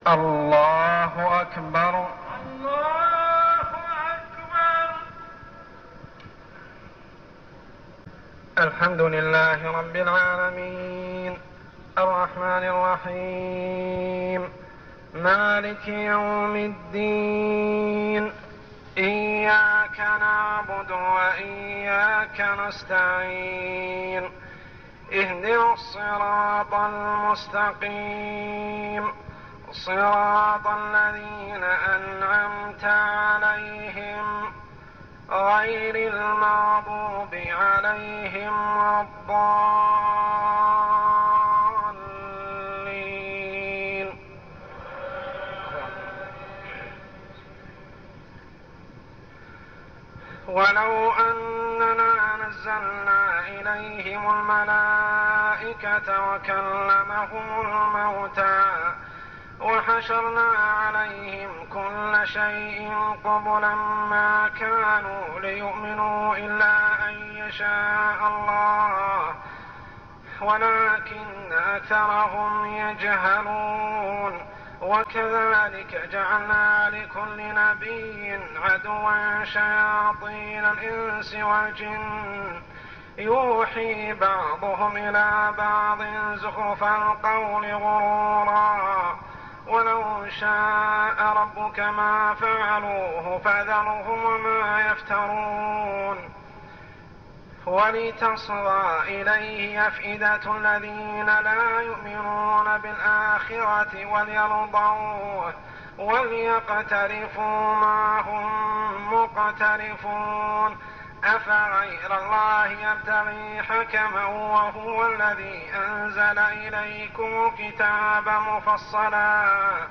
صلاة التراويح ليلة 8-9-1413هـ سورتي الأنعام 111-165 و الأعراف 1-30 | Tarawih prayer Surah Al-An'am and Al-A'raf > تراويح الحرم المكي عام 1413 🕋 > التراويح - تلاوات الحرمين